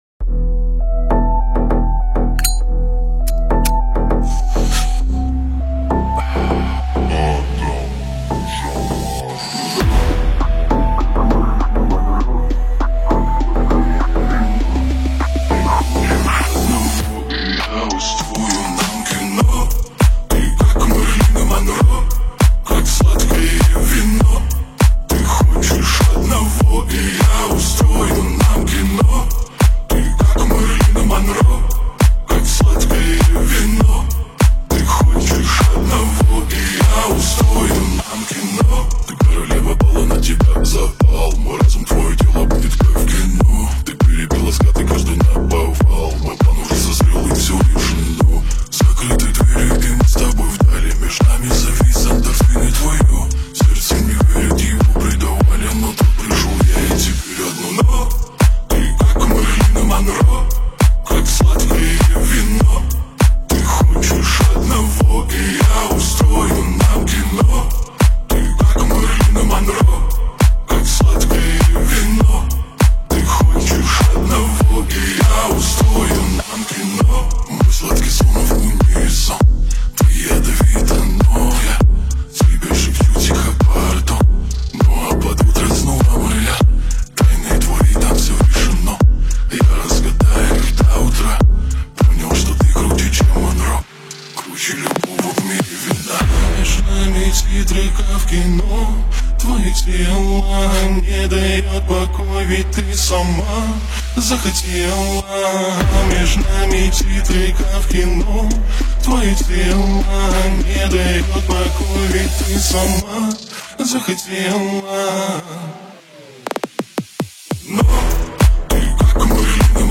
Remix BassBoosted